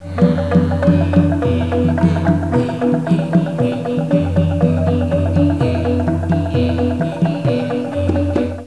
Java, bambou (374 Kb)
Un gamelan est un ensemble instrumental traditionnel indonésien composé principalement de percussions : gongs, métallophones, xylophones, tambours, cymbales, flûtes.